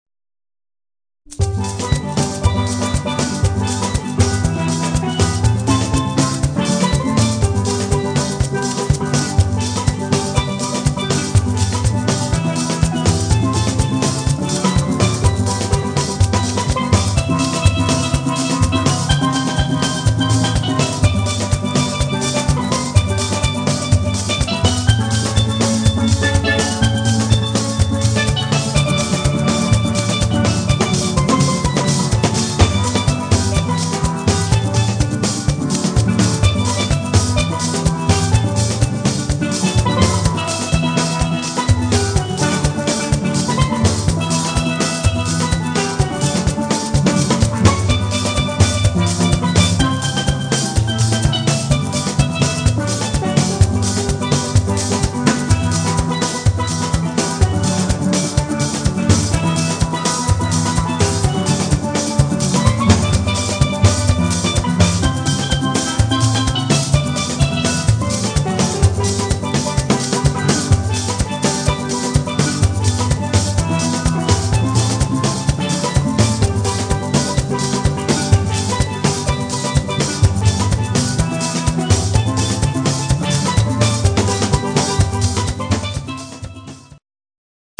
• Authentic Caribbean musicians and music
• Perfect to evoke sunny carnival ambience
• Versatile traditional steelpan ensemble